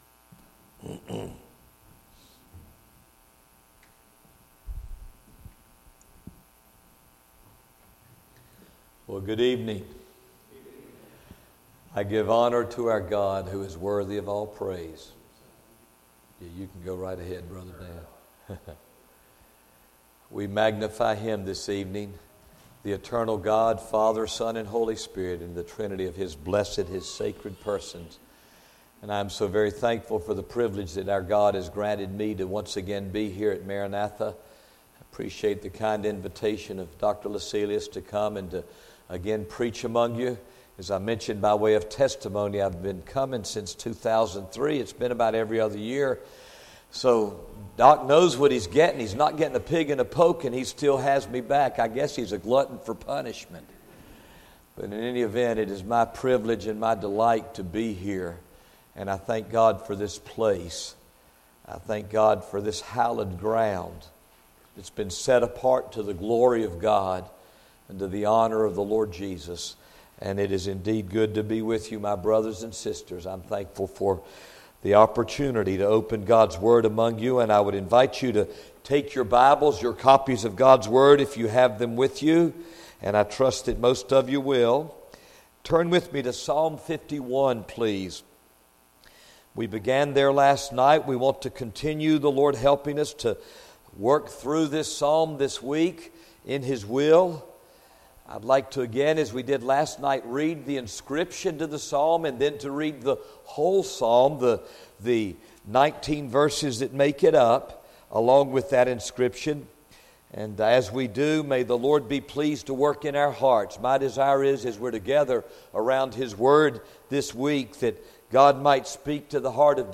Session: Evening Session